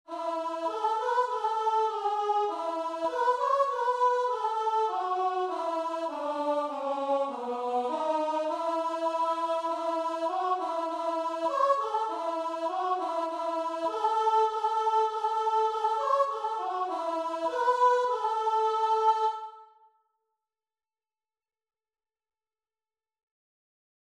4/4 (View more 4/4 Music)
Classical (View more Classical Guitar and Vocal Music)